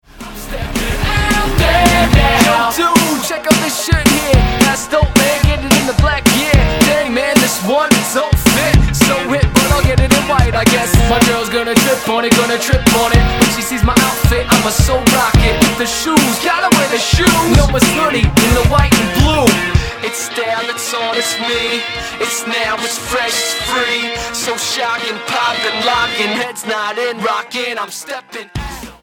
Style: Hip-Hop